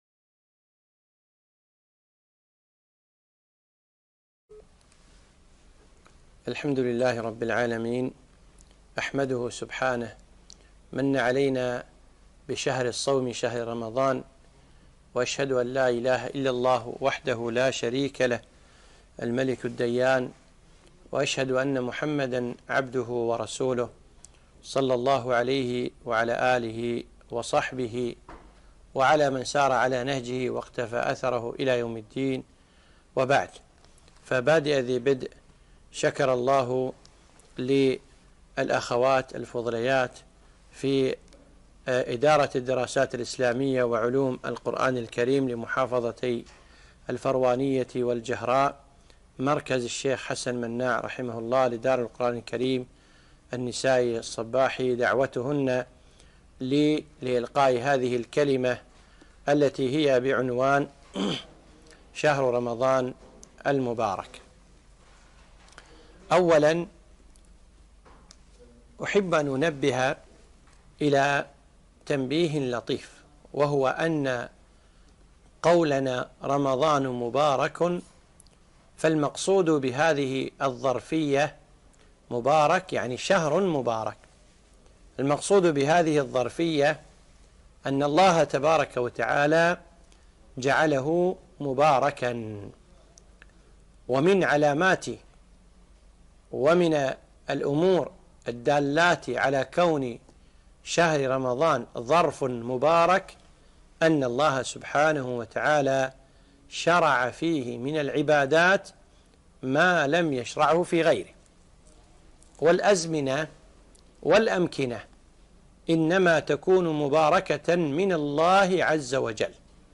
محاضرة - شهر رمضان المبارك